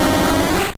Cri de Noctali dans Pokémon Or et Argent.